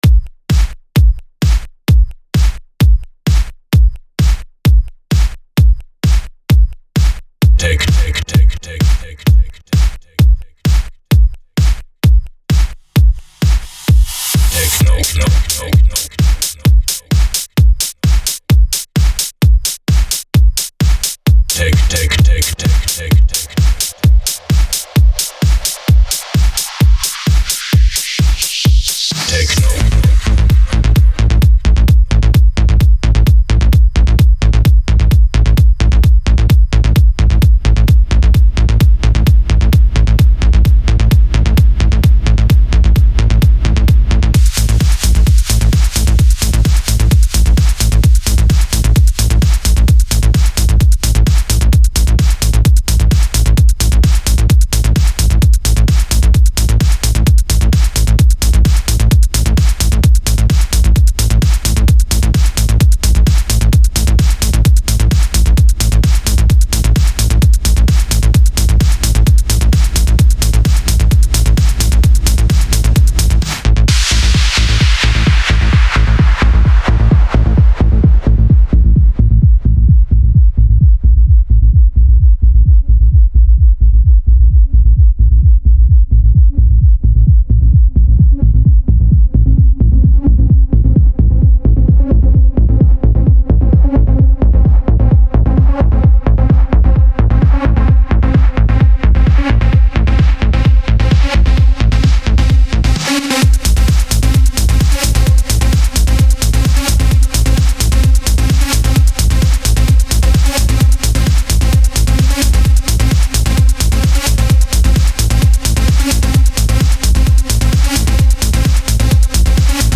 Стиль: Techno